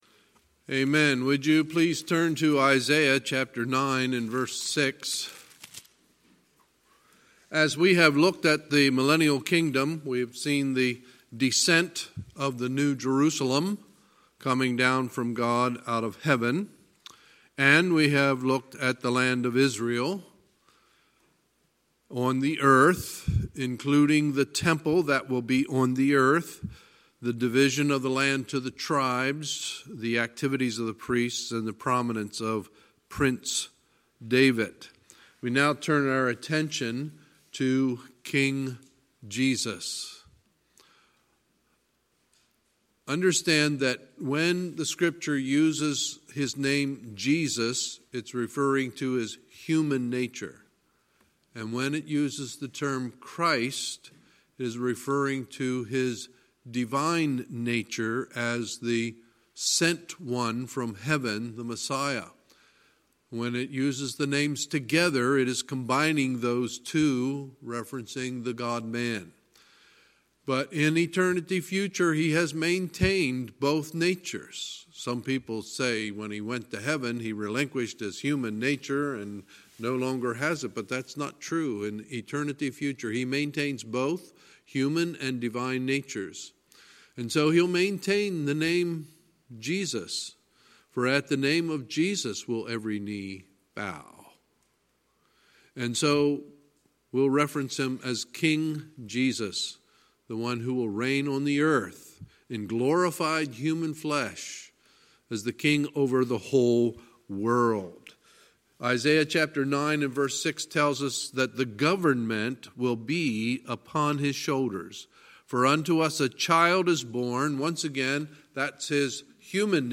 Sunday, October 6, 2019 – Sunday Evening Service